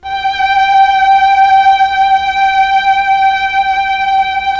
STR_TrnVlnG_5.wav